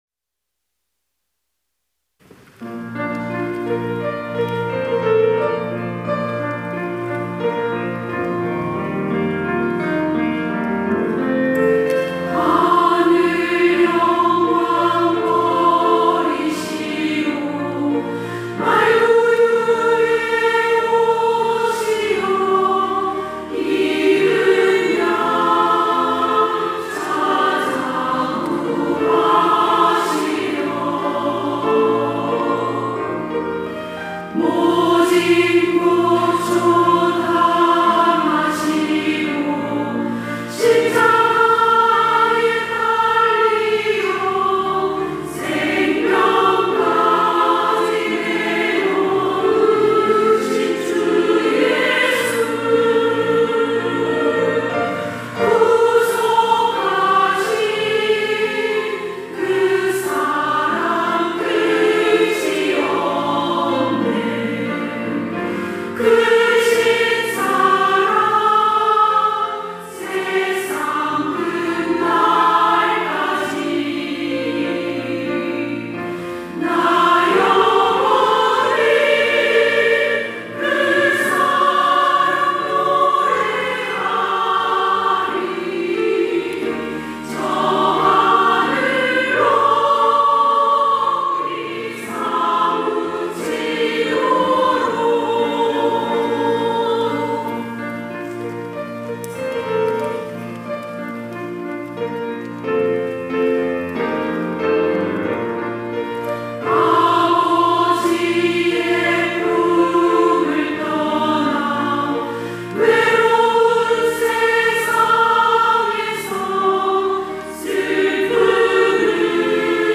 여전도회 - 구속의 사랑
찬양대